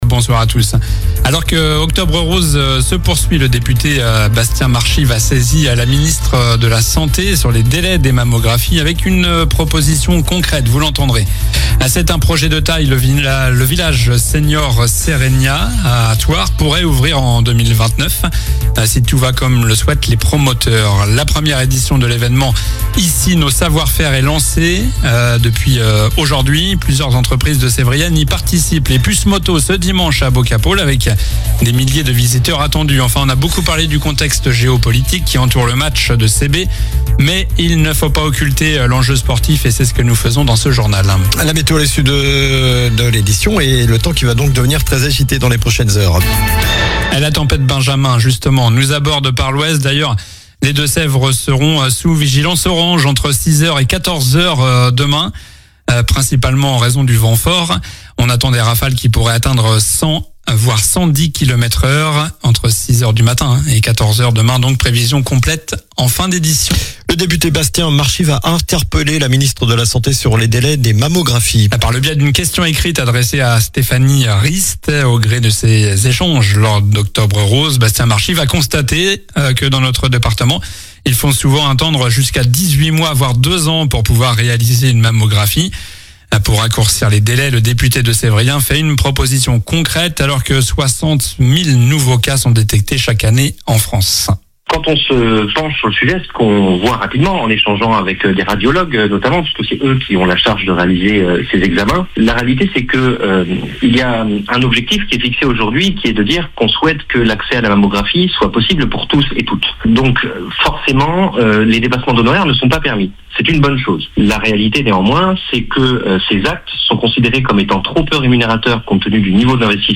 Journal du mercredi 22 octobre (soir)